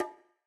ITA Conga 1.wav